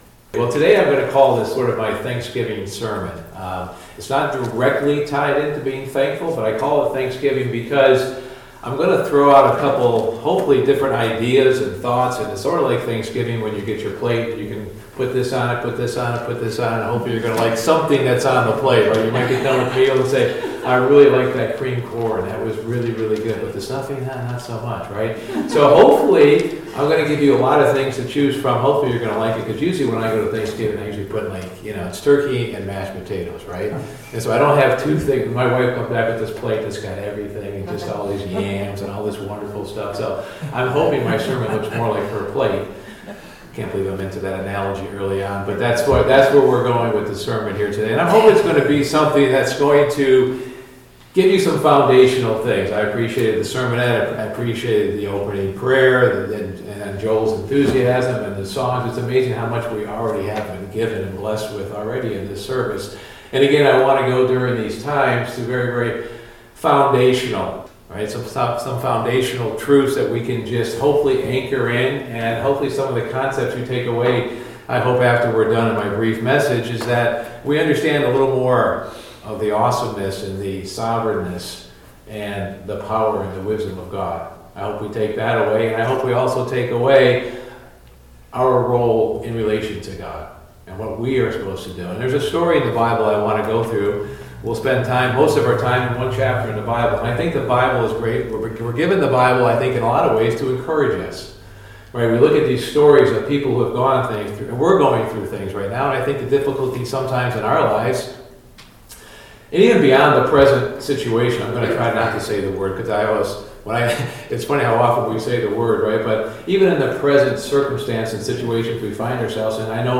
We need to remember the awesomeness, power and wisdom of God at these times. This sermon takes a look at King Jehoshaphat's example.